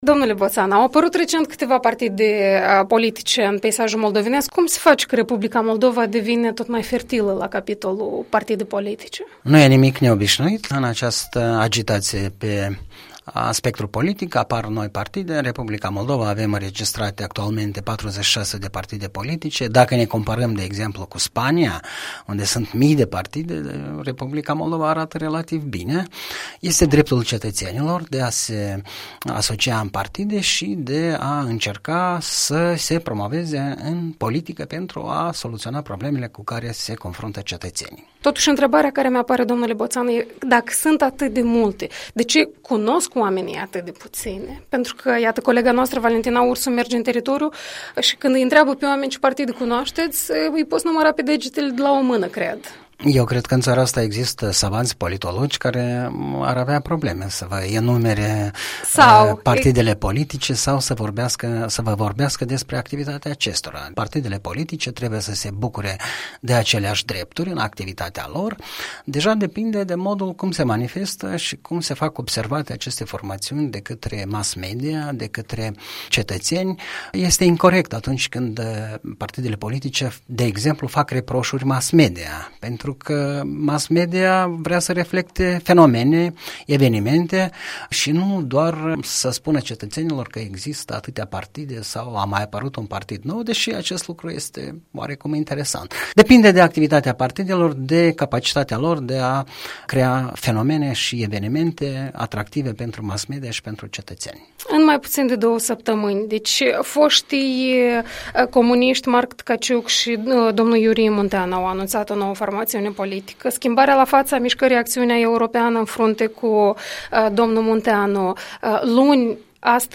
Comentariu săptămânal, în dialog la Europa Liberă.